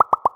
Special Pop (4).wav